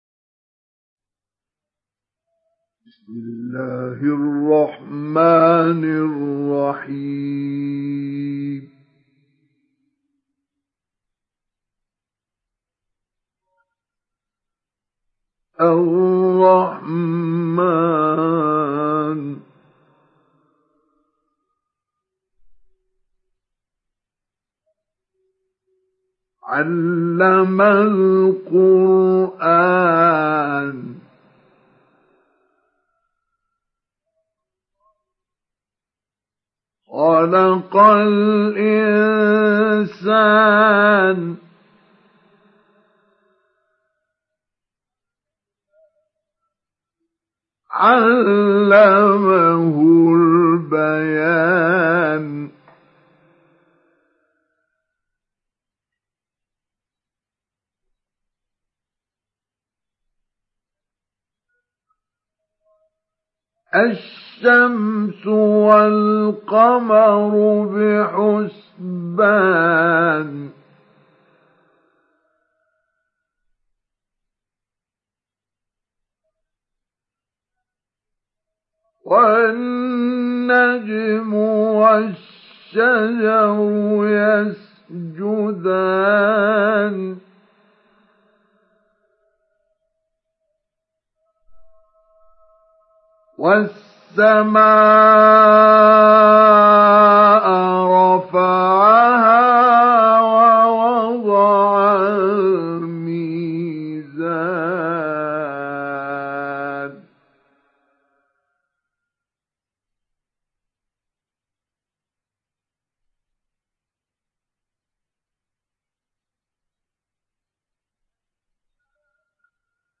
Surat Ar Rahman Download mp3 Mustafa Ismail Mujawwad Riwayat Hafs dari Asim, Download Quran dan mendengarkan mp3 tautan langsung penuh
Download Surat Ar Rahman Mustafa Ismail Mujawwad